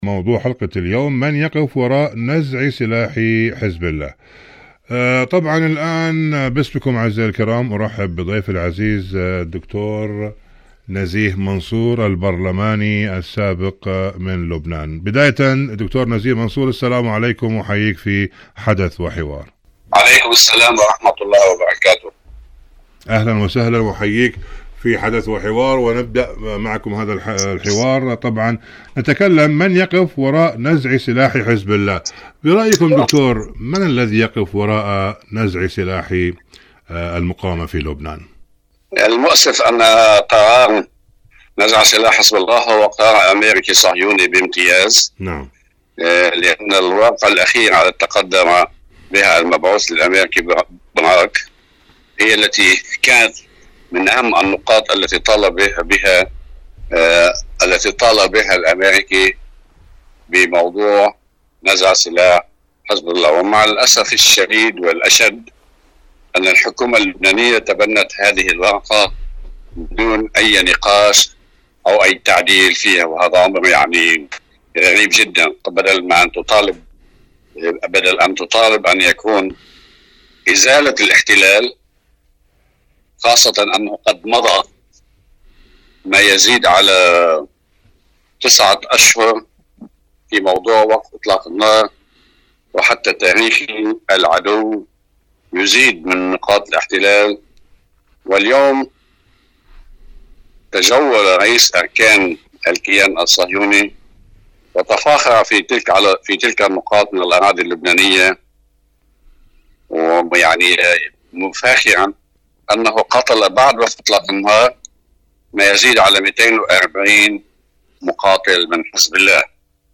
إذاعة طهران- حدث وحوار: مقابلة إذاعية مع الدكتور نزيه منصور الخبير القانوني والبرلماني السابق من لبنان حول موضوع من يقف وراء نزع سلاح حزب الله؟